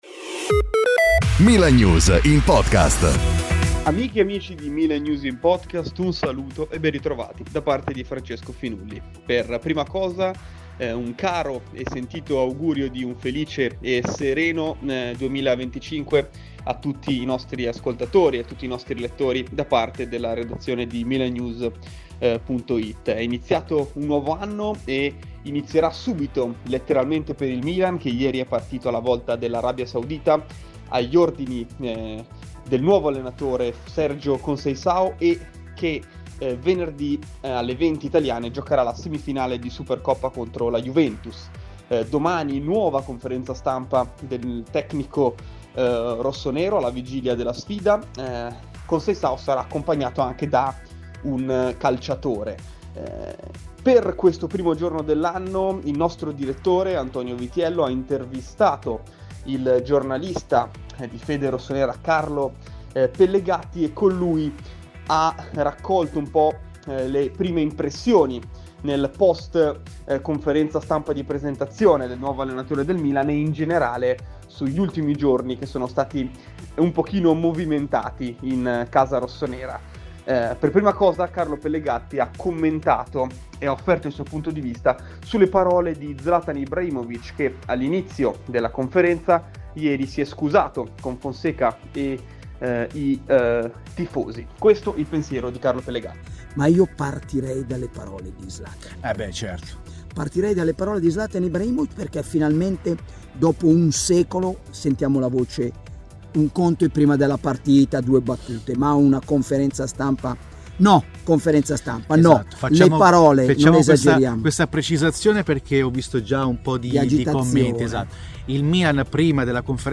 Ne parliamo con Carlo Pellegatti, storico giornalista che da una vita segue le vicende rossonere.